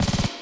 59LOOP SD4-R.wav